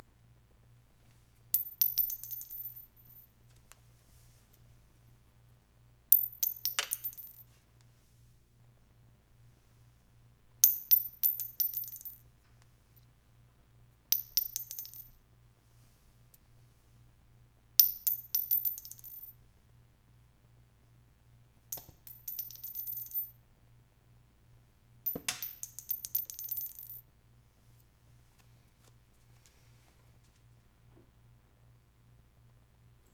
shell
bullet bullet-casings casing ding ejected empty shell unedited sound effect free sound royalty free Voices